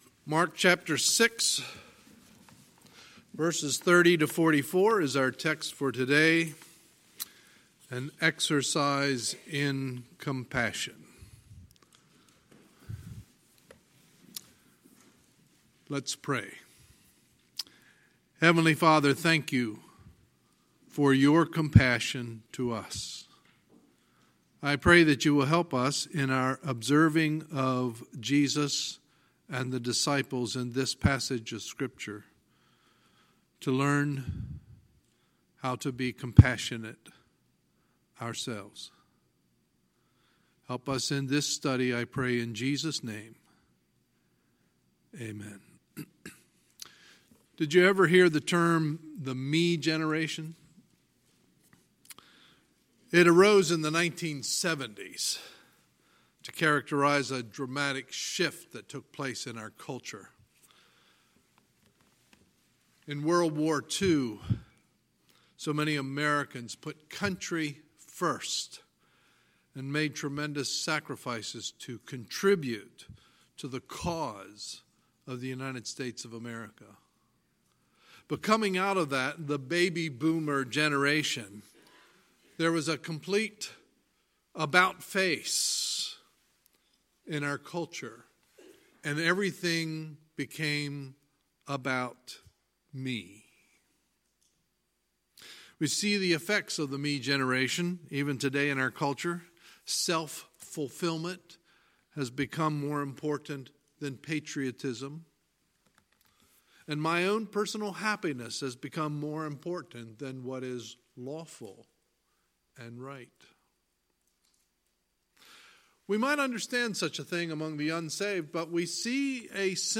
Sunday, June 2, 2019 – Sunday Morning Service